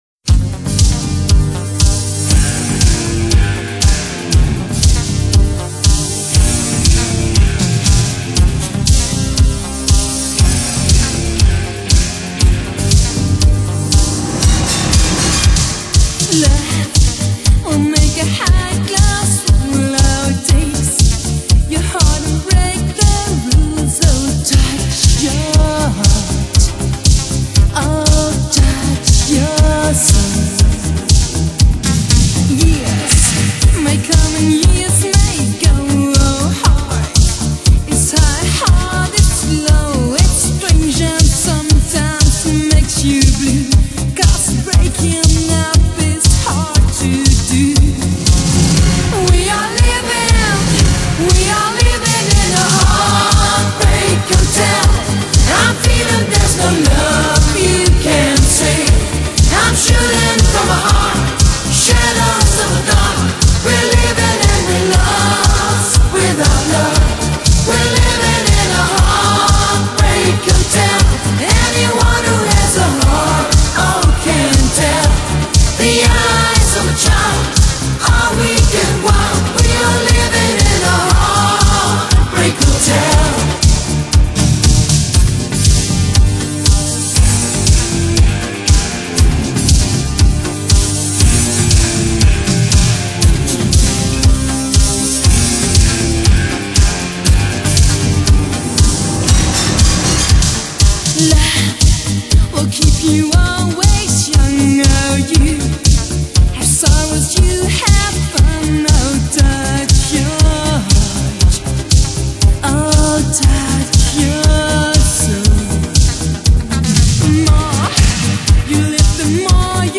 车载音响测试碟
百听不厌的经典舞曲，音响低频测试的最佳标尺。